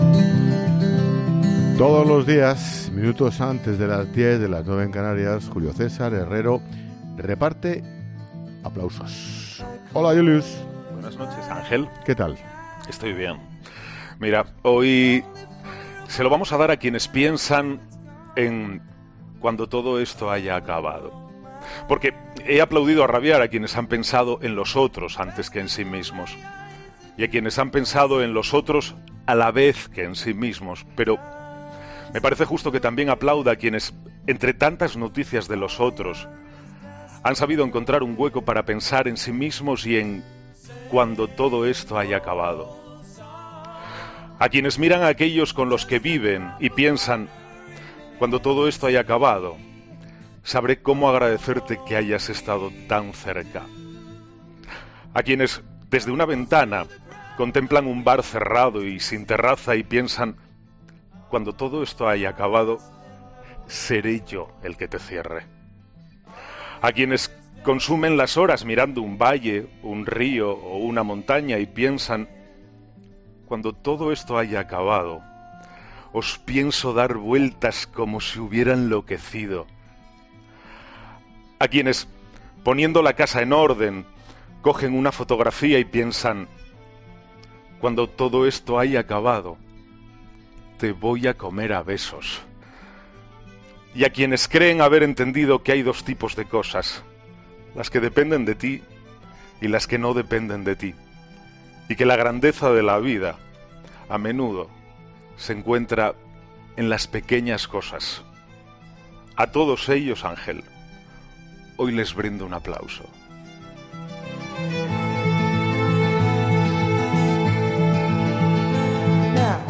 "A todos ellos, hoy les brindo un aplauso", ha dicho en un mensaje cargado de emoción en 'La Linterna' de Ángel Expósito